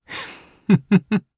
Roman-Vox_Happy1_jp_b.wav